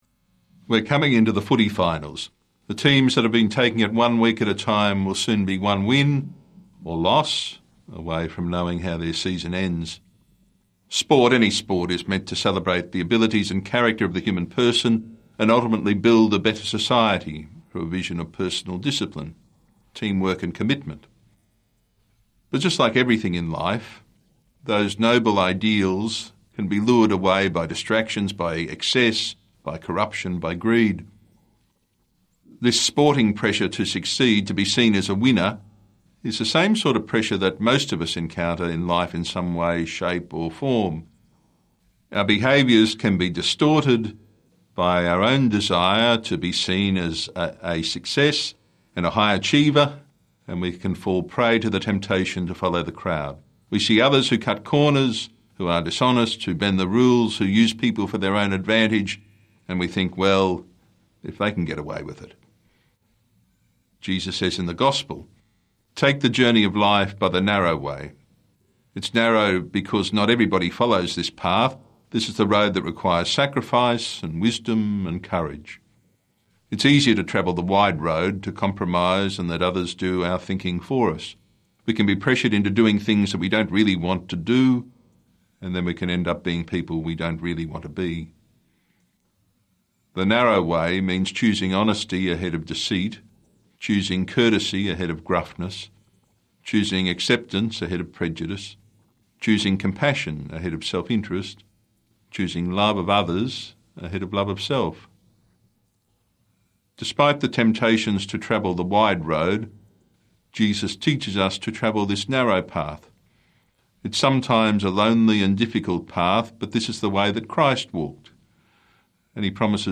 Archdiocese of Brisbane Twenty-First Sunday in Ordinary Time - Two-Minute Homily